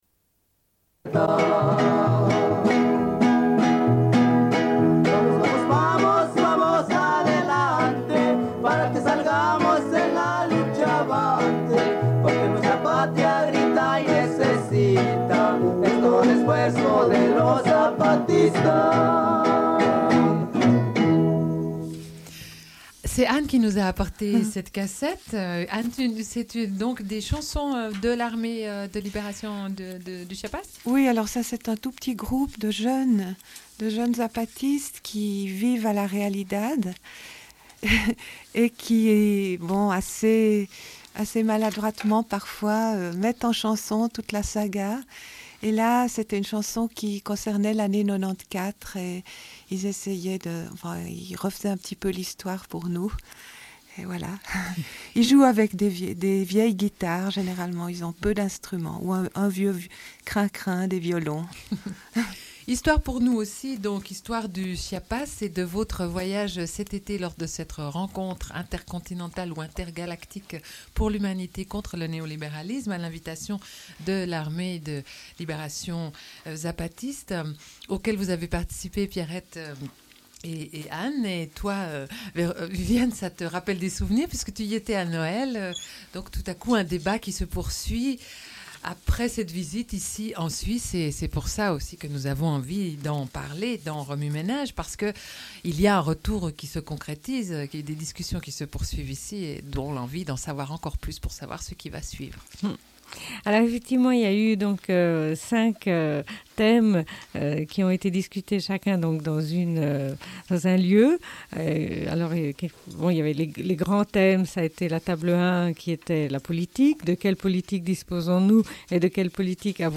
Une cassette audio, face B28:57
00:00:21 - Musique zapatiste. Discussion autour de cinq thèmes : Politique; Économie; Culture; Social; Question indigène.